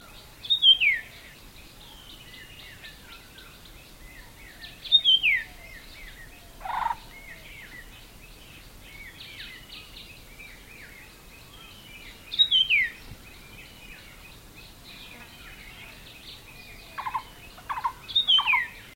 letöltés) Aegithina tiphia - Common Iora XC125847.ogg .